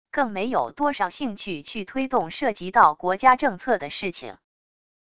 更没有多少兴趣去推动涉及到国家政策的事情wav音频生成系统WAV Audio Player